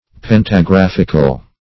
Search Result for " pentagraphical" : The Collaborative International Dictionary of English v.0.48: Pentagraphic \Pen`ta*graph"ic\, Pentagraphical \Pen`ta*graph"ic*al\, a. [Corrupted fr. pantographic, -ical.]
pentagraphical.mp3